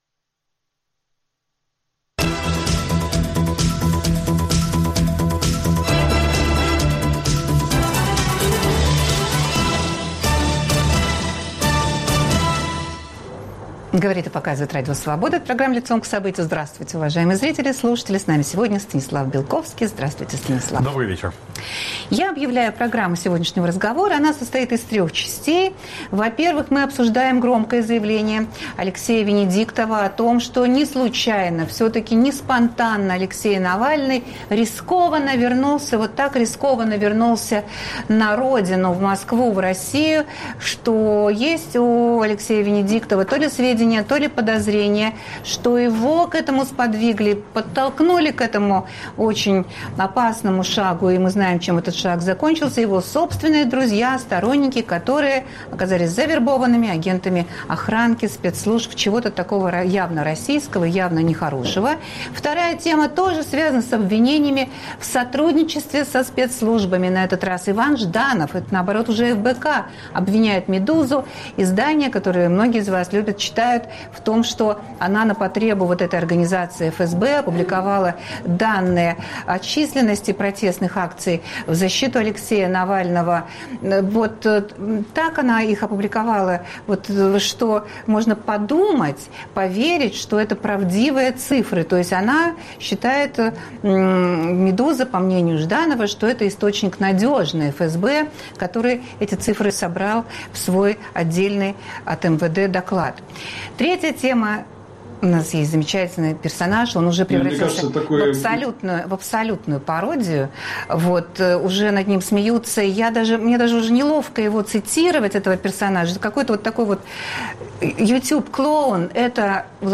Гость студии - политолог Станислав Белковский.